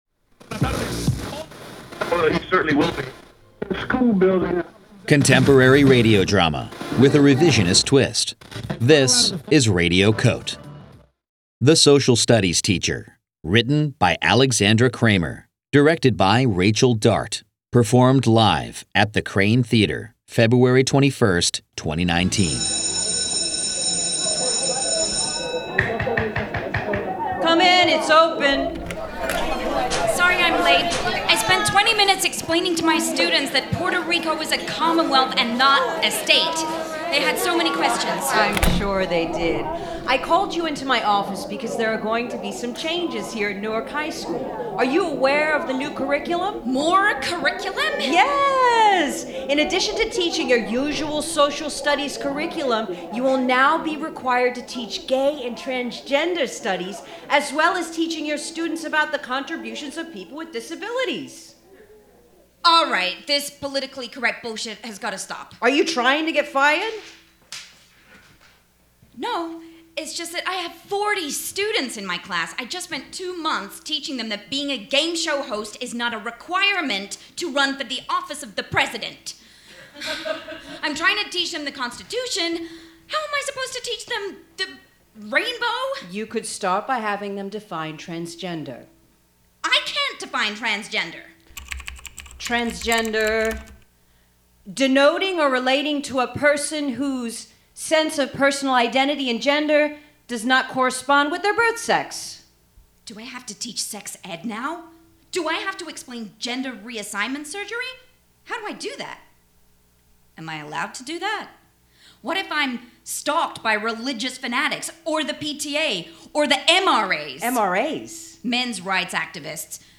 performed for Radio COTE: 24-hour Newsroom in the FRIGID Festival, February 21, 2019